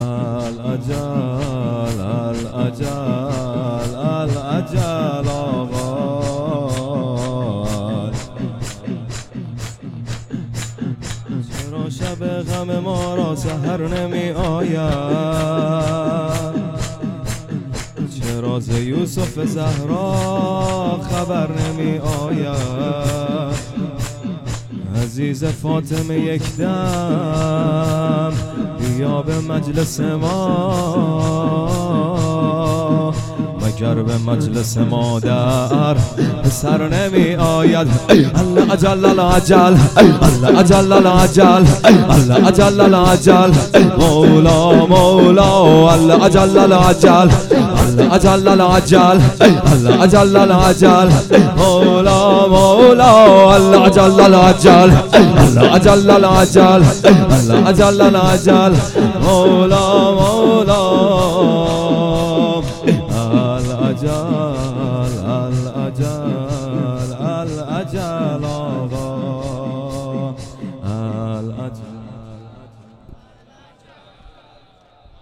شور _ العجل العجل